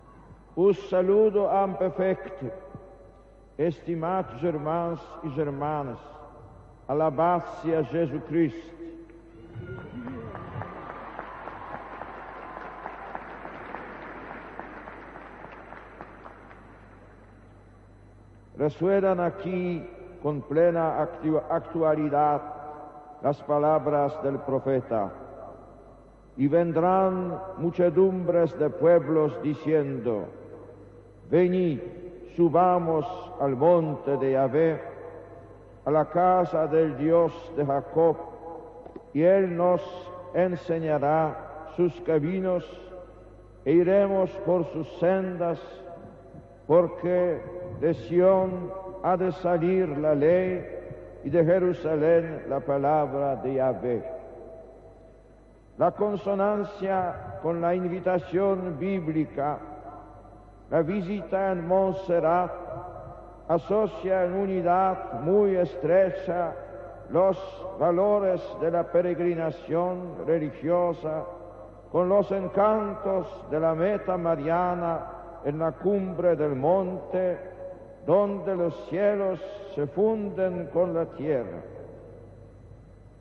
59eae63c02c0bc0ba6eccbd834f683f8fe7e13c4.mp3 Títol Ràdio 4 Emissora Ràdio 4 Cadena RNE Titularitat Pública estatal Descripció Paraules del Sant Pare Joan Pau II en la seva visita al Monestir de Montserrat.